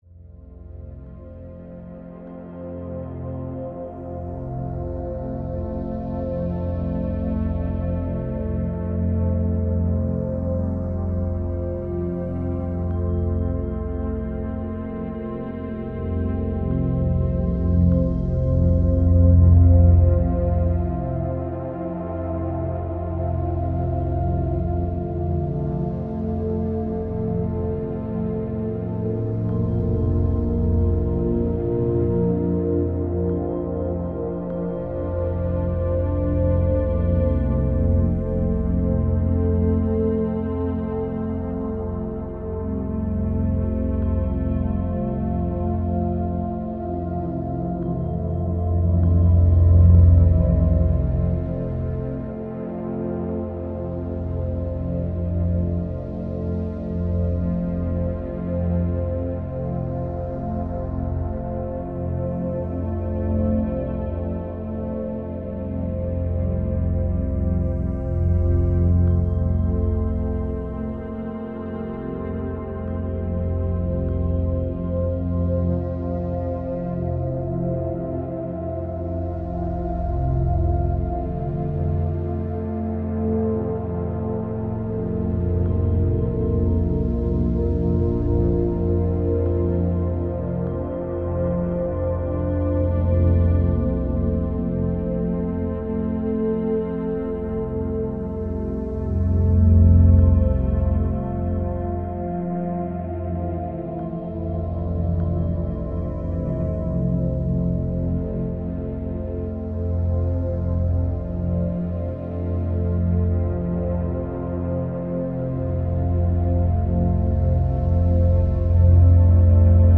meditative track